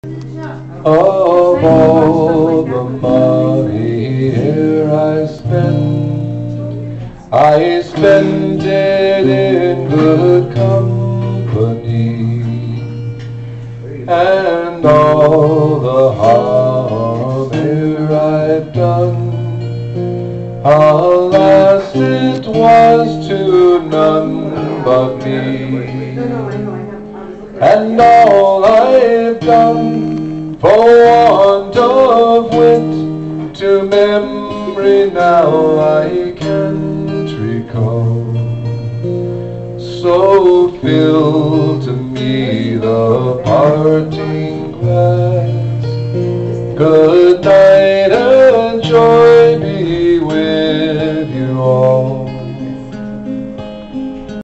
Irish Songs